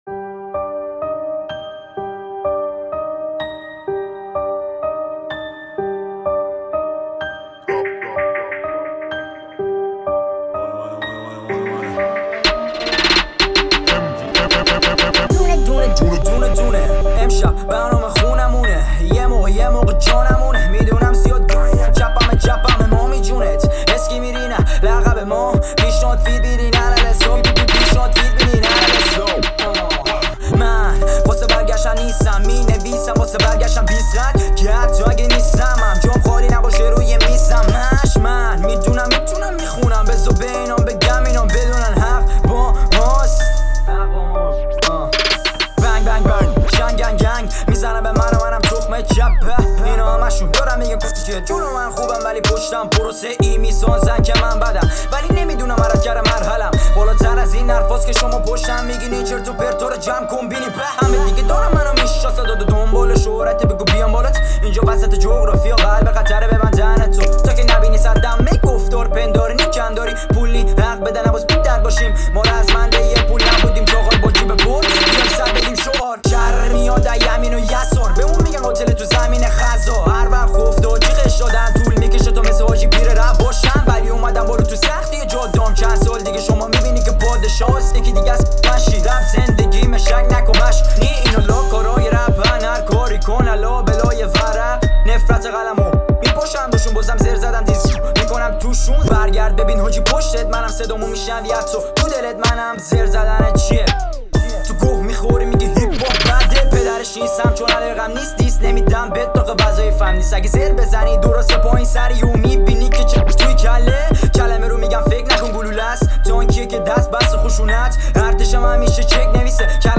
Rap Male